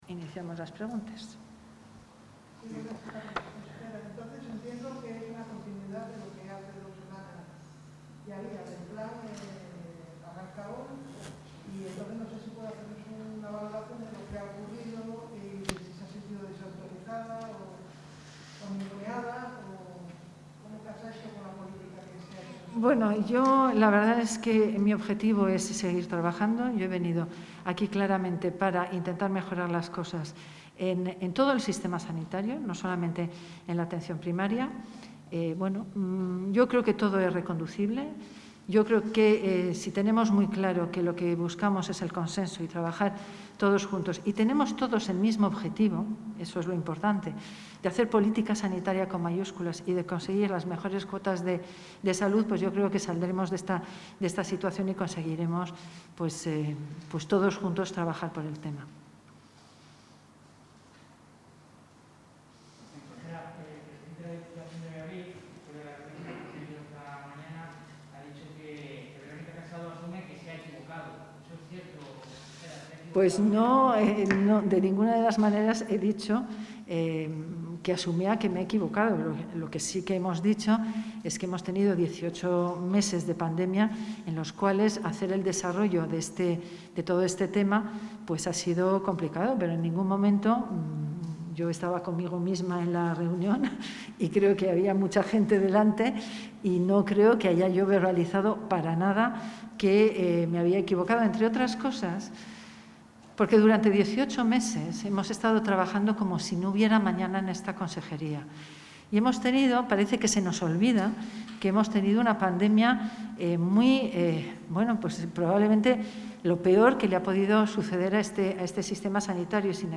Intervención de la consejera de Sanidad.